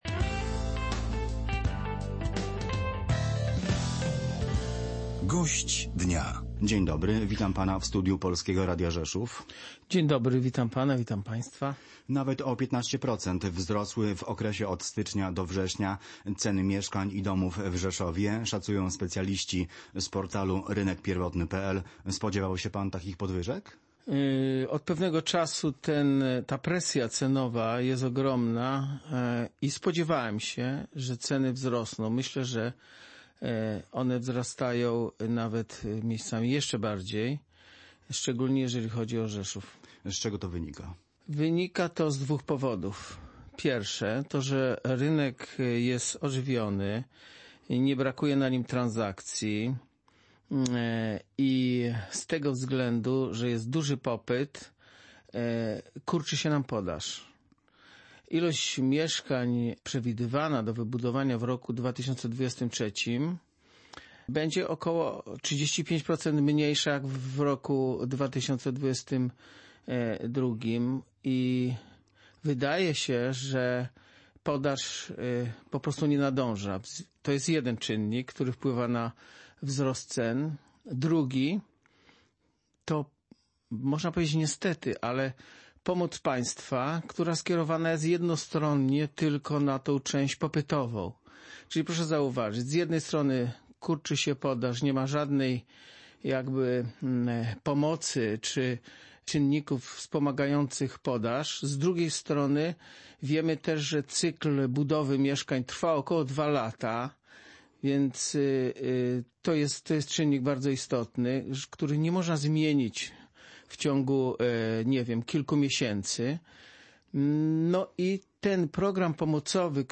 gościem dnia w Polskim Radiu Rzeszów